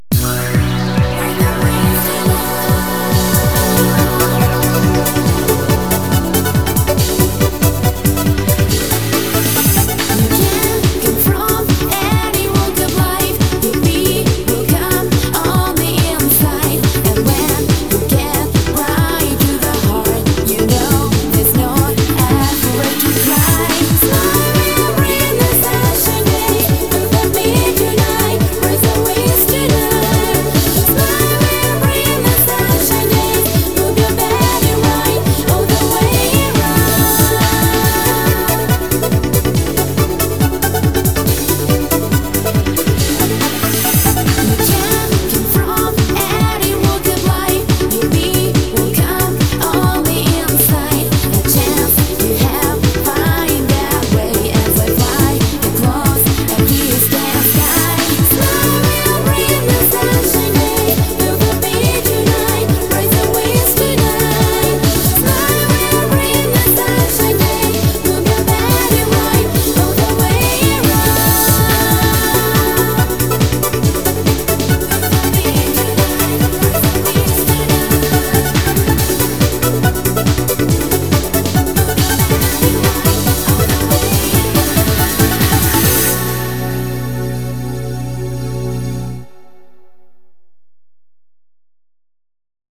BPM140
Audio QualityPerfect (High Quality)
Better quality audio.